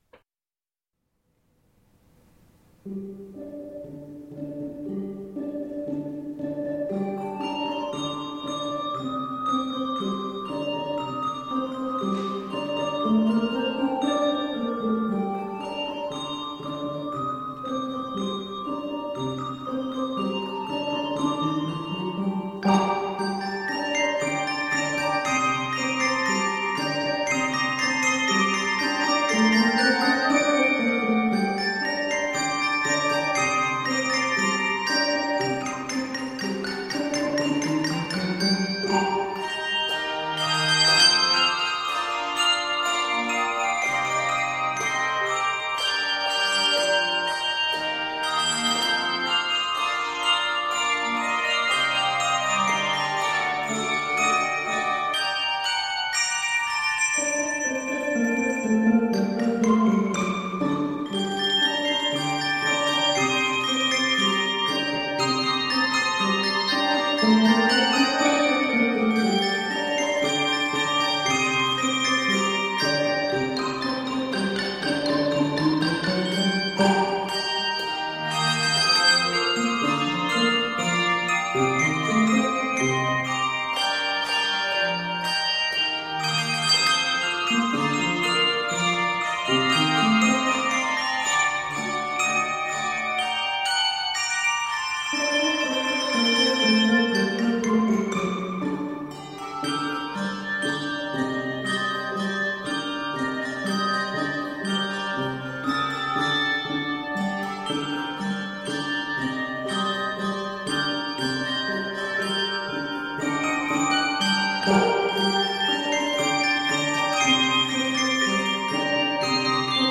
Set in g minor, it is 66 measures.